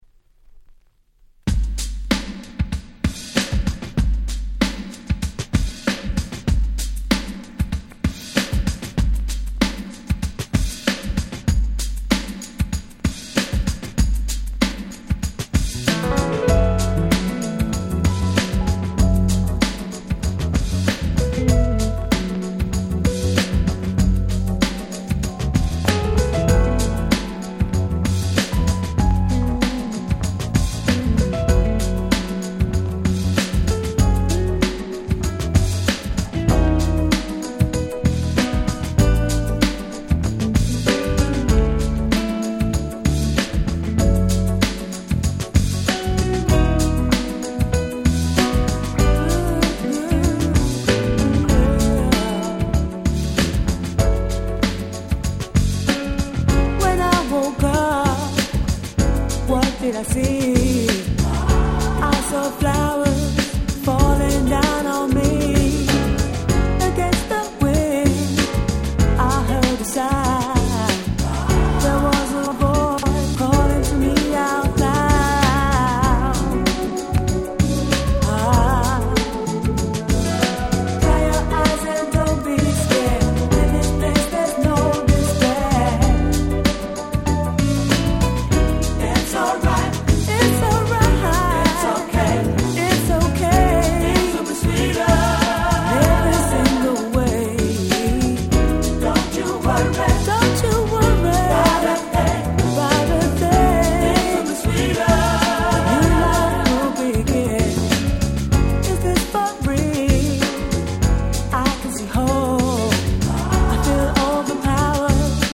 95' Nice UK Soul !!
サバービア誌にも掲載された最高にSmoothな1曲。
強めのBeatの上を流れる様な美しいメロディーがめちゃくちゃ気持ちの良い素晴らしい1曲です！
鉄板UK Soul !!